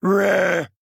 AV_bear_exclaim.ogg